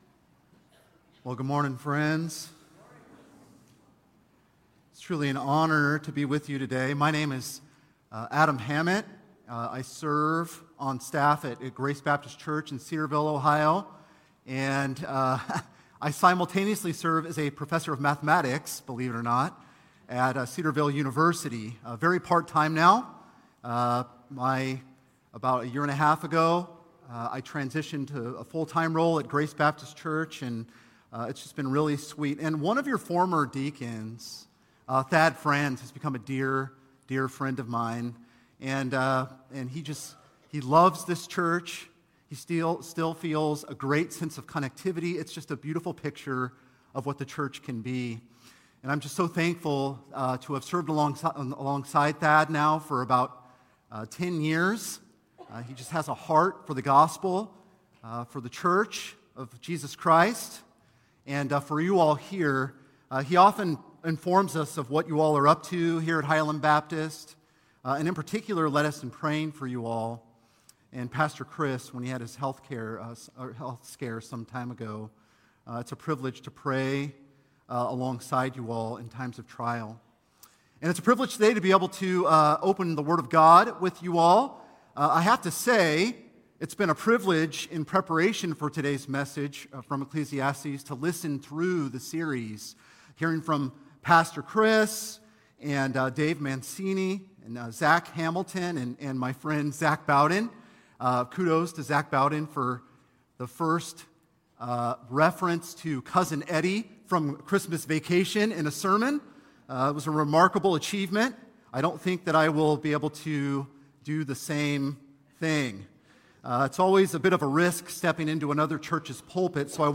Highland Baptist Church Sermons